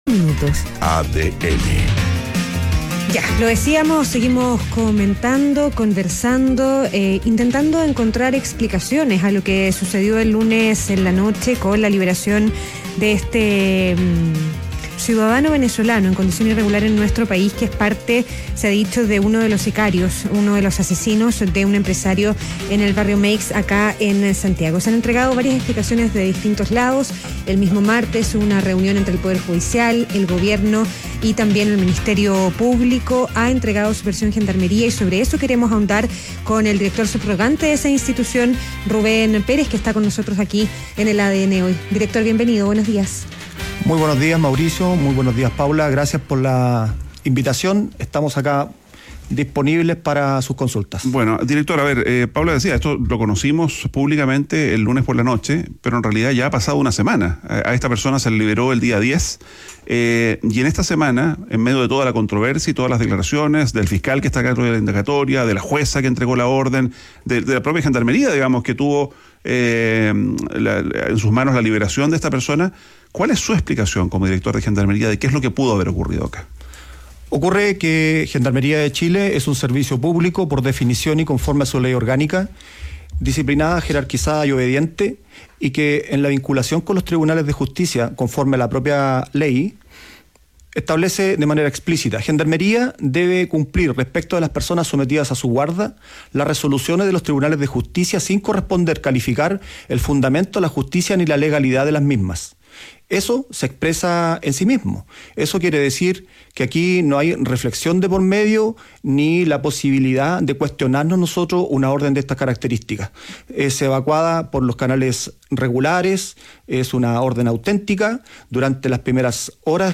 Entrevista a Rubén Pérez, director (s) de Gendarmería - ADN Hoy